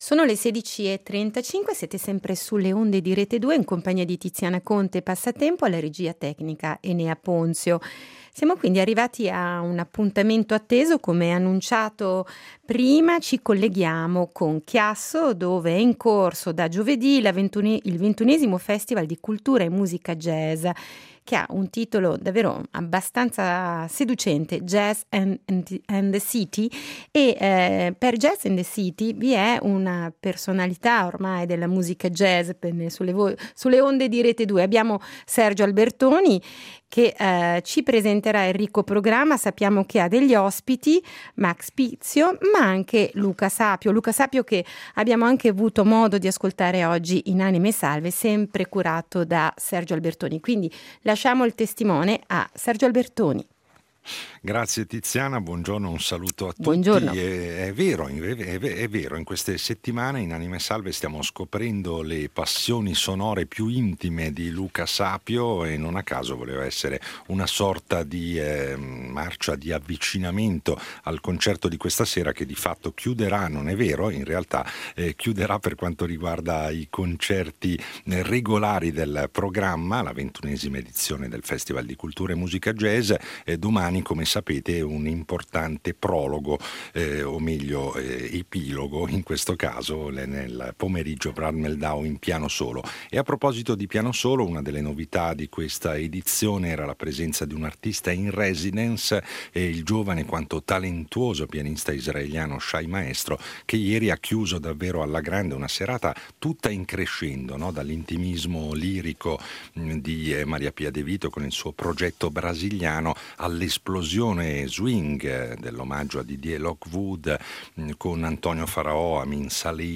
Collegamenti in diretta con la 21° edizione del Festival di cultura e musica Jazz di Chiasso